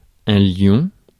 Ääntäminen
IPA: [ljɔ̃]